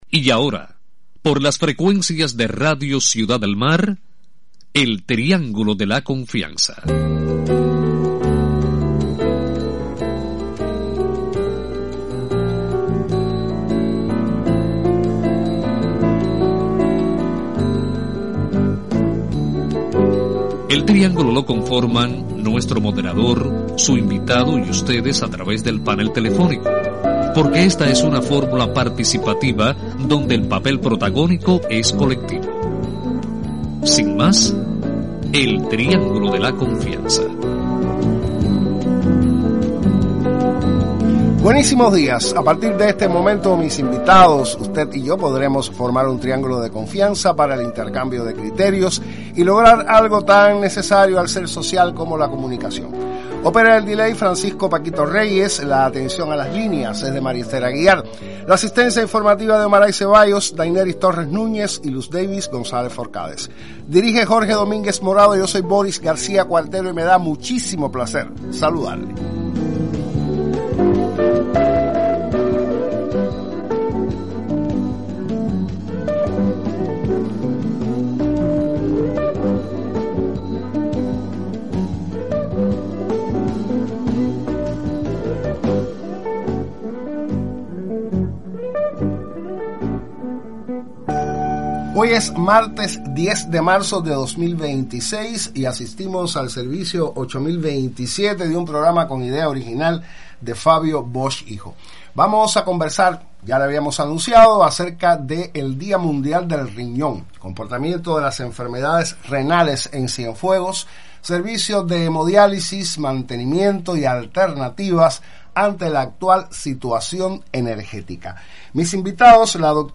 son los invitados al Triángulo de la confianza para dialogar sobre el comportamiento de las enfermedades renales en Cienfuegos